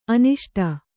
♪ aniṣṭa